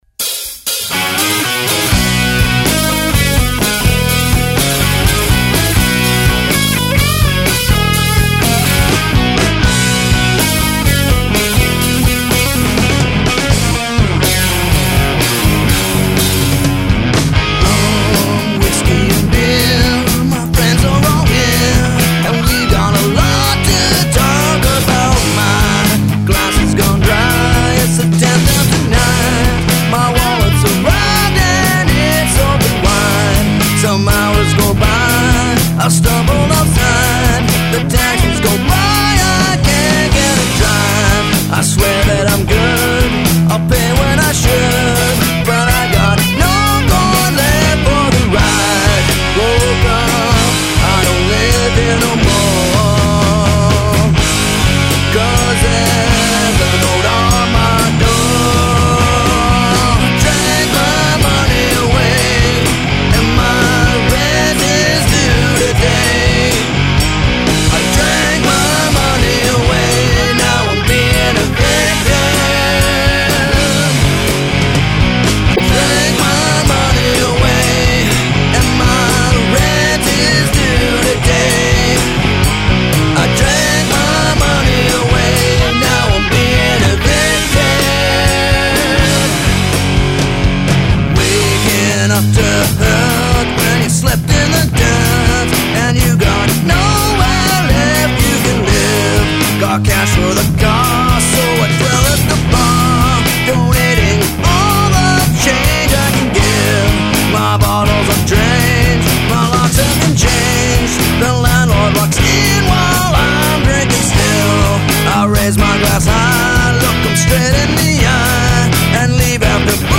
Evicted Master Song"; written, played and sung by him and his band.